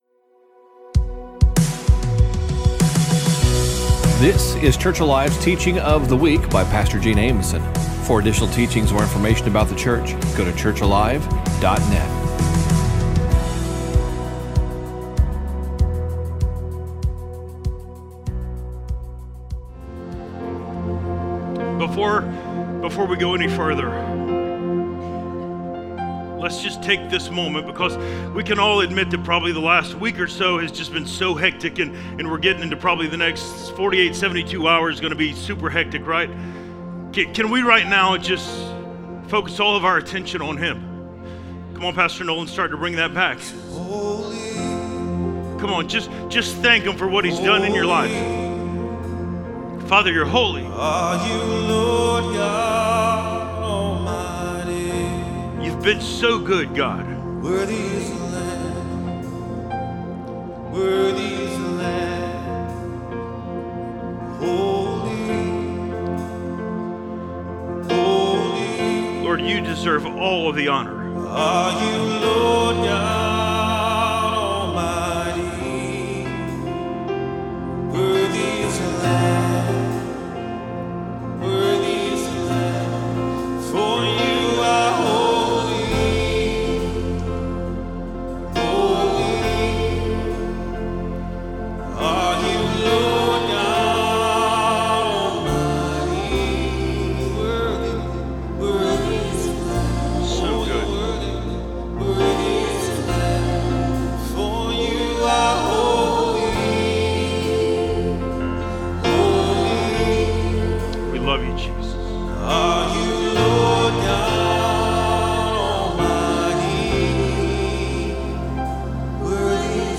2025 Christmas Candlelight Service